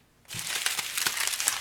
PaperCrumble.ogg